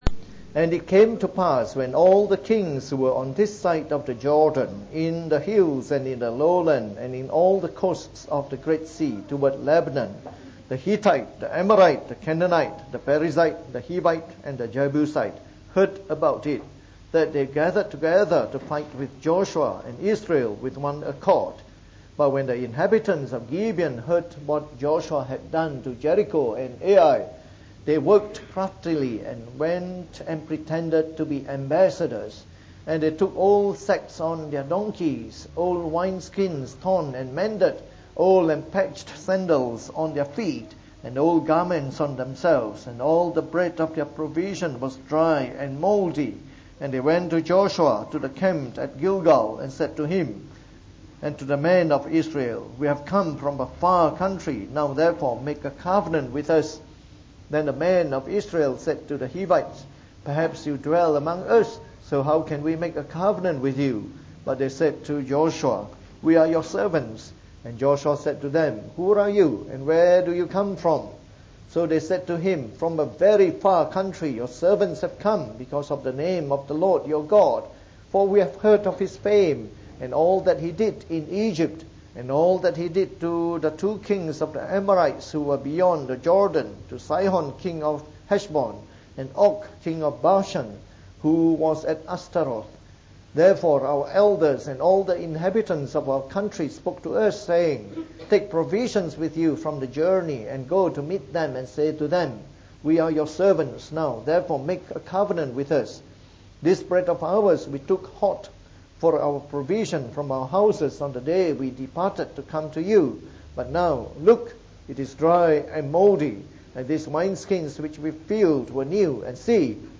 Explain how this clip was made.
Preached on the 28th of September 2014. From our series on the Book of Joshua delivered in the Morning Service.